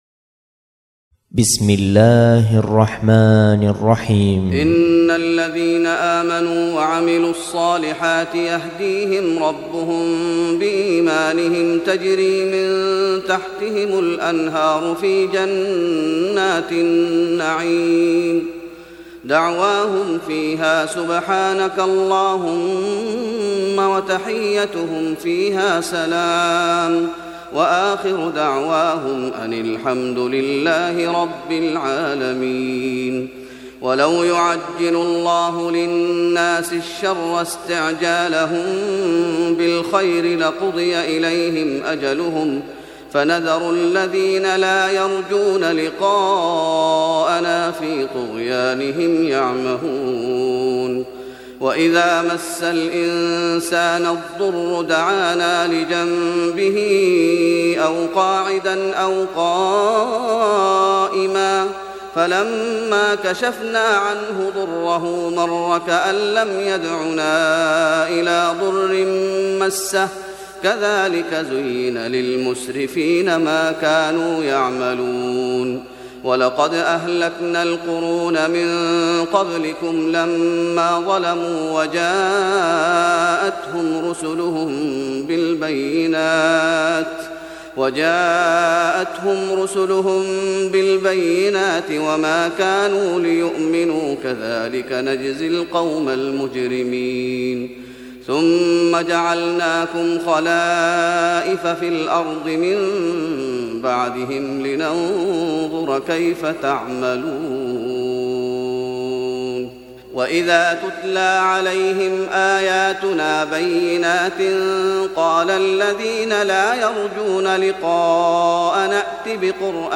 تراويح رمضان 1415هـ من سورة يونس (9-36) Taraweeh Ramadan 1415H from Surah Yunus > تراويح الشيخ محمد أيوب بالنبوي 1415 🕌 > التراويح - تلاوات الحرمين